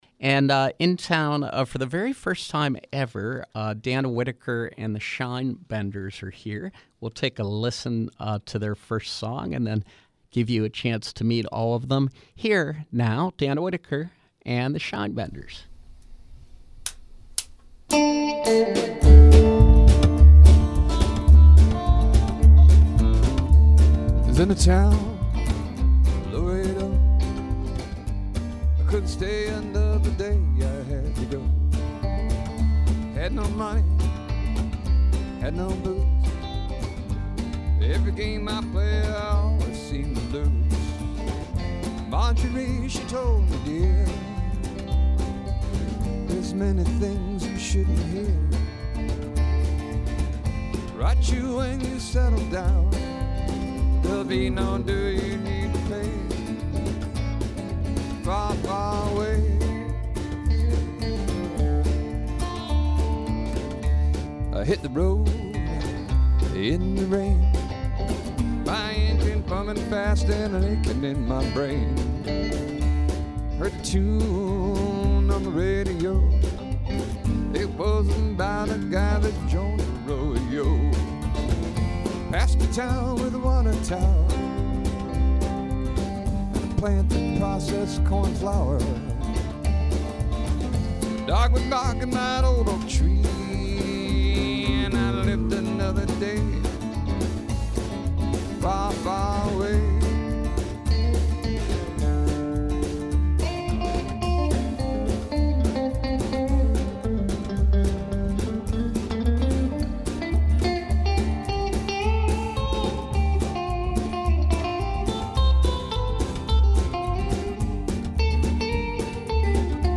Live music with Chicago-based rockabilly band